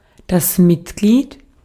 Ääntäminen
Synonyymit lul pik piemel plasser deksel penis gewricht aanhanger vennoot lidmaat geleding jongeheer associé term leuter snikkel partijganger gelid partijlid Ääntäminen Tuntematon aksentti: IPA: /lɪt/